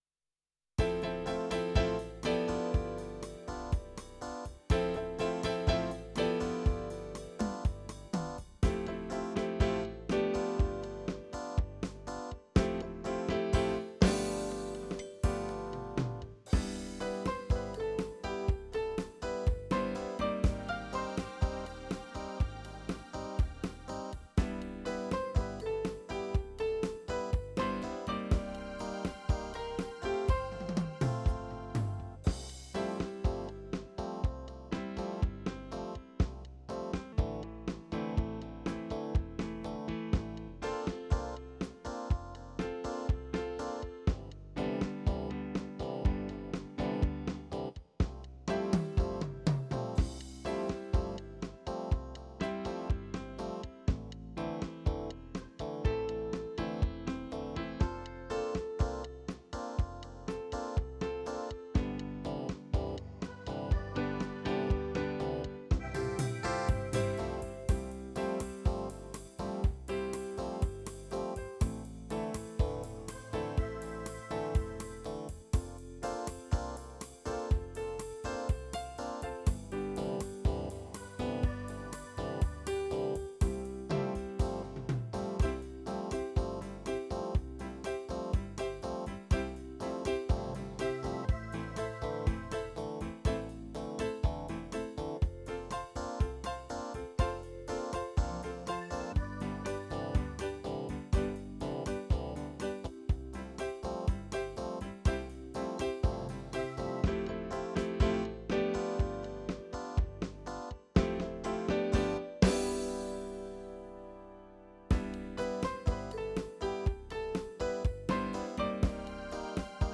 Bossabrazil